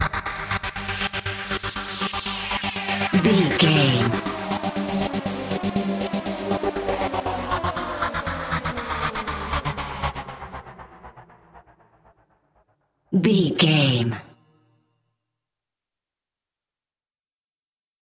Epic / Action
Aeolian/Minor
drum machine
synthesiser
electric piano
Eurodance